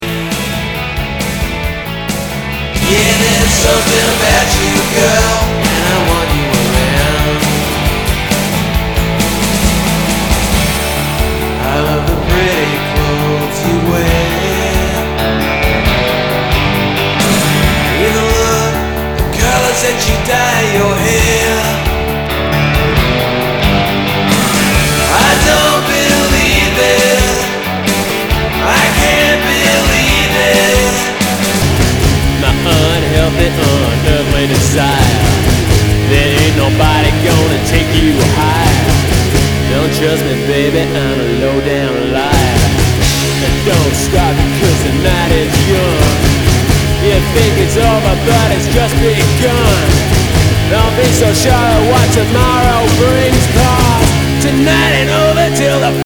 ROCK/POPS/INDIE
ナイス！インディーロック！！